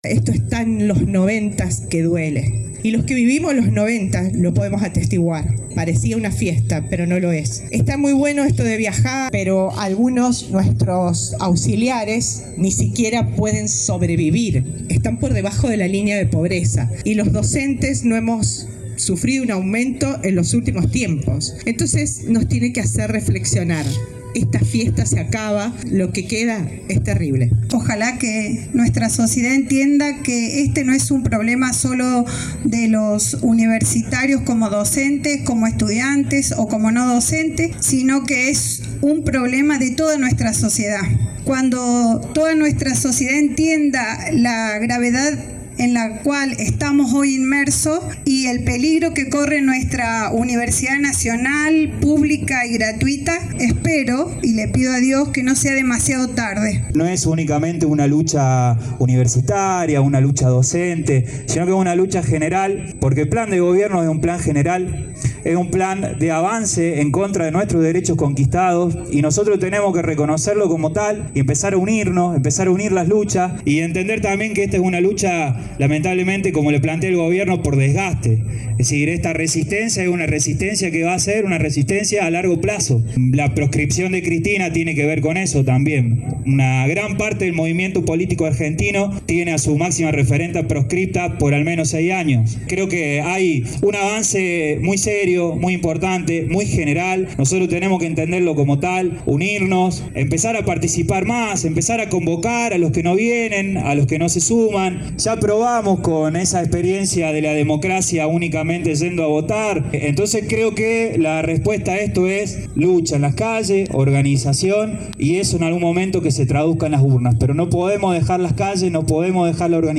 Para aportar adherentes a esta iniciativa, en la concentración de ayer en plaza San Martín se instaló una mesa con planillas donde los manifestantes pudieron firmar su adhesión al proyecto de ley.